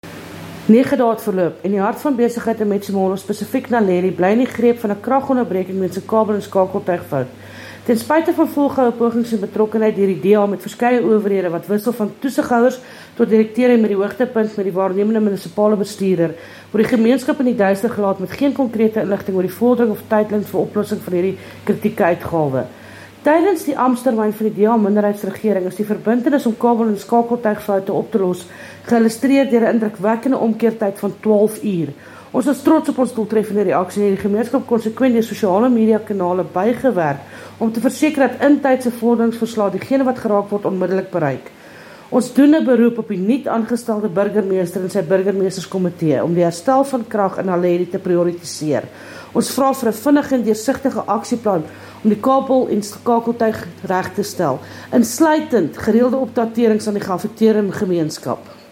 Afrikaans soundbites by Cllr Linda Day and